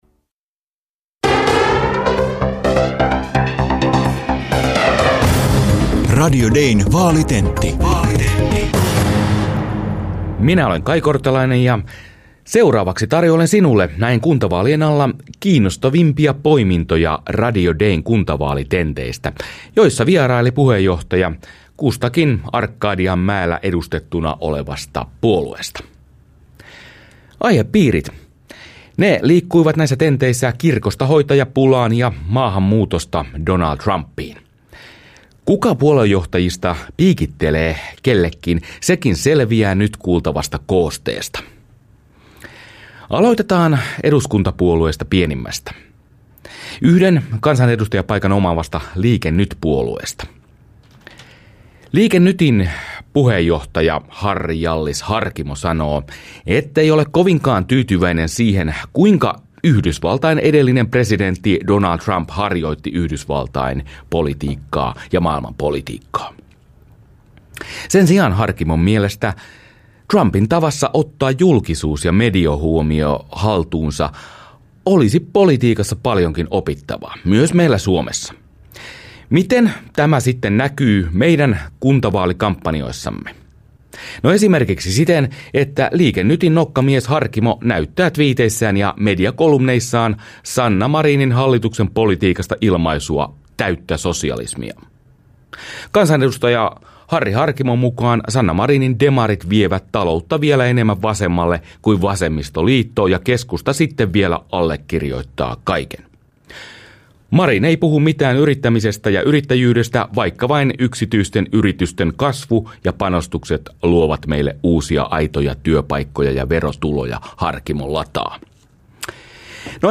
Radio Dei tenttasi kuntavaalien alla eduskuntapuolueiden puheenjohtajat. Mitä sanottiin hoitajapulasta, maahanmuutosta ja nuorten kokemista paineista?
Koostelähetys puoluejohtajatenttien parhaista paloista keskiviikkona 2.6. aamuyhdeksän jälkeen.